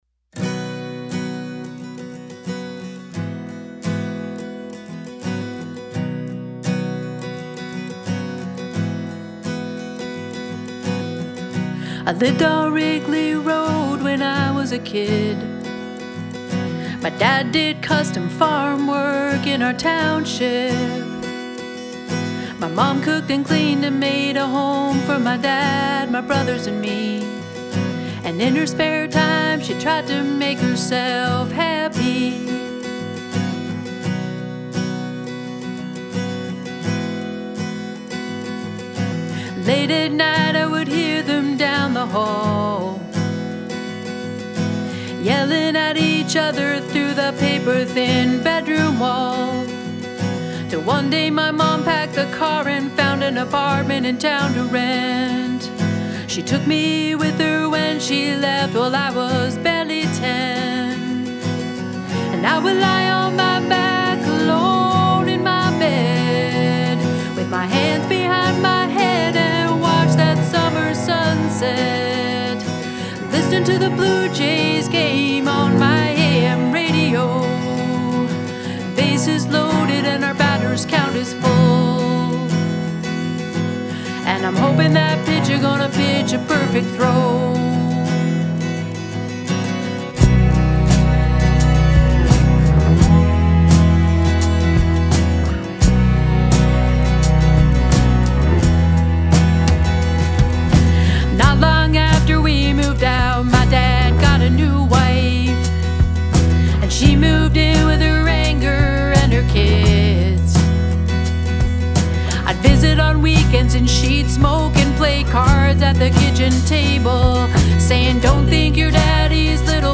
vocals, acoustic guitar
cello, drums
bass